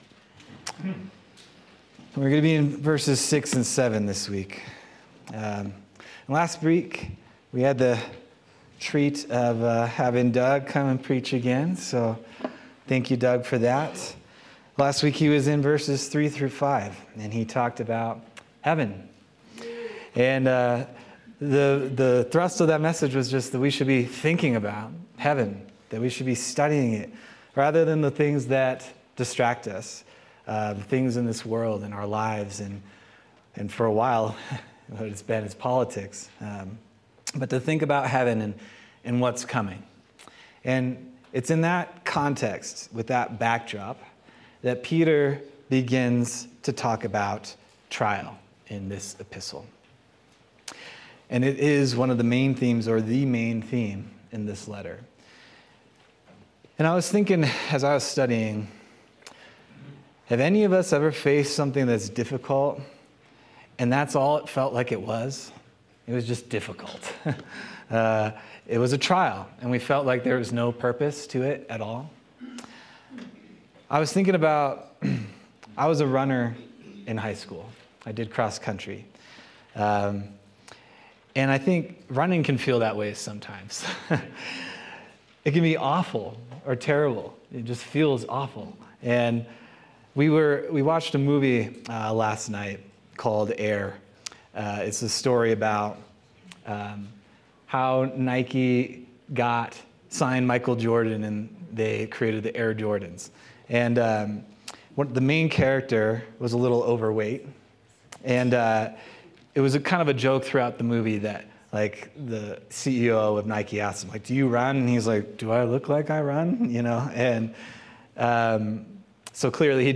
November 24th, 2024 Sermon